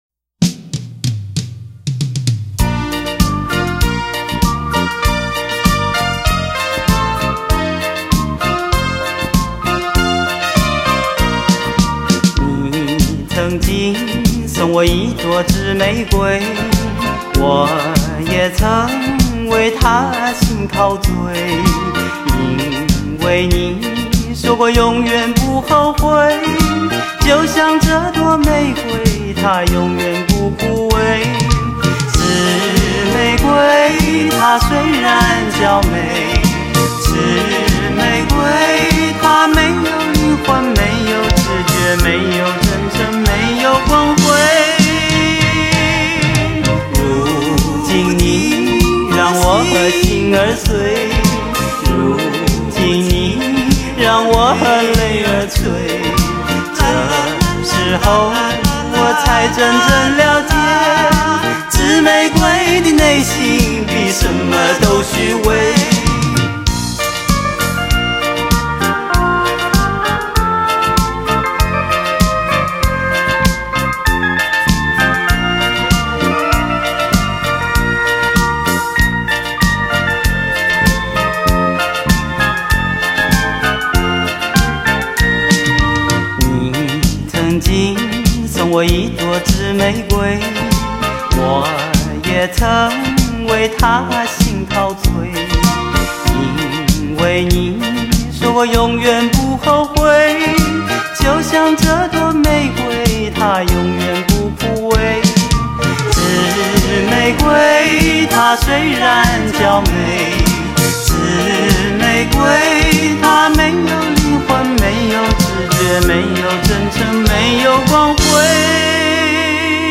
专辑歌曲的调性虽以抒情为主，
旋律却呈现着多元的风貌